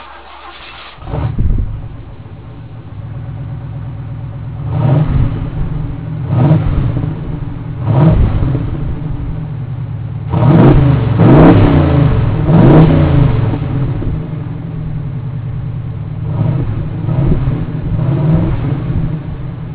My previous 85 LTD LX 5.0 HO
That's POWER, baby!
Yes, I am a computer nerd, and no, I don't have a life =Þ The door to my garage is right near my computer, so I stuck the computer mic out the door and revved the car (my previous LTD LX).
LXrev.wav